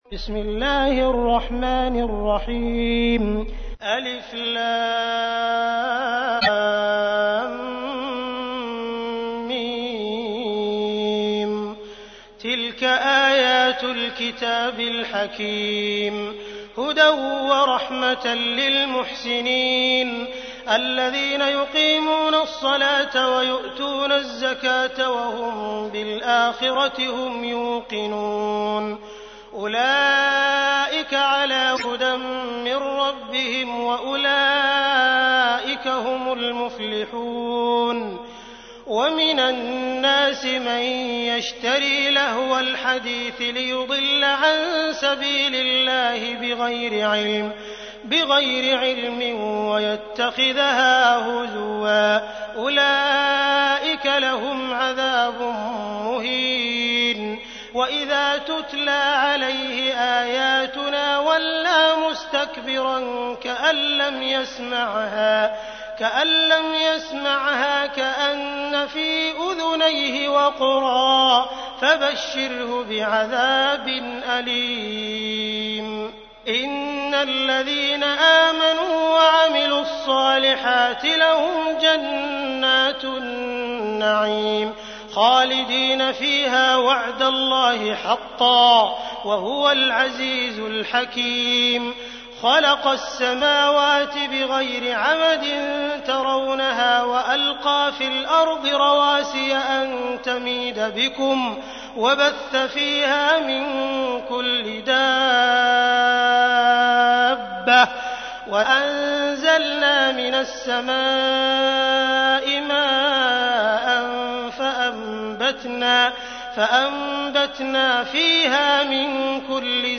تحميل : 31. سورة لقمان / القارئ عبد الرحمن السديس / القرآن الكريم / موقع يا حسين